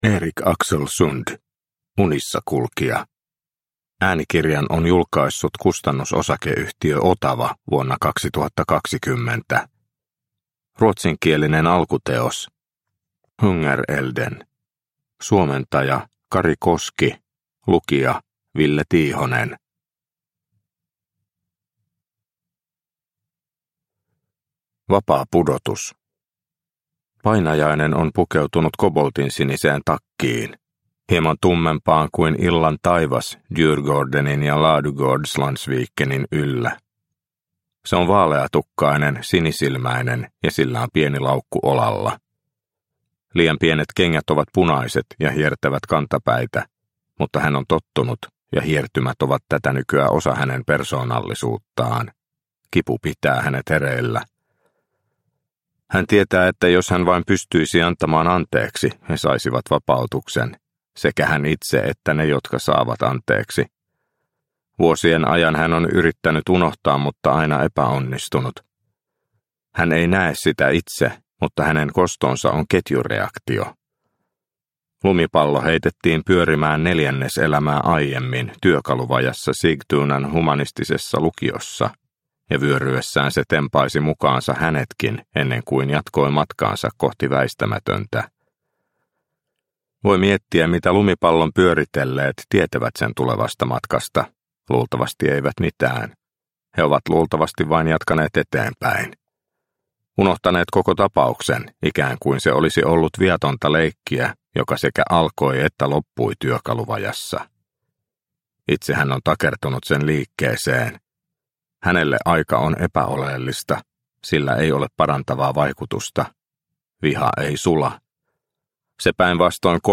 Unissakulkija – Ljudbok – Laddas ner